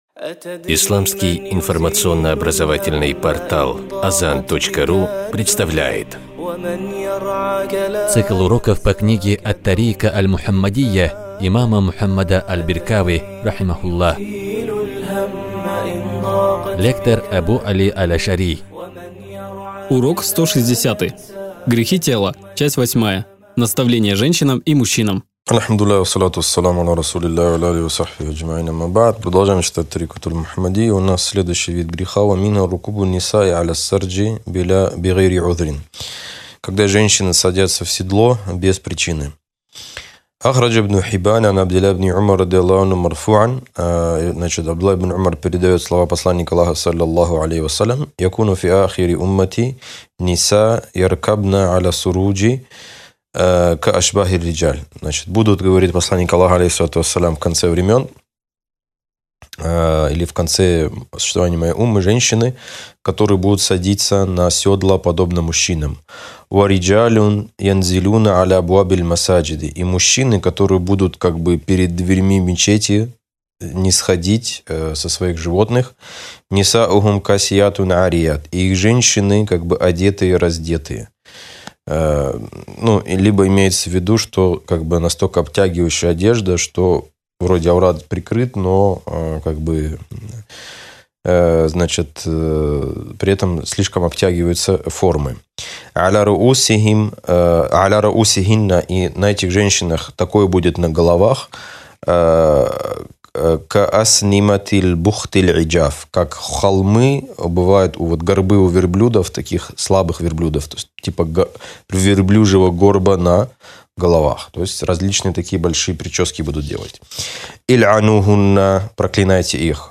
аудио-уроки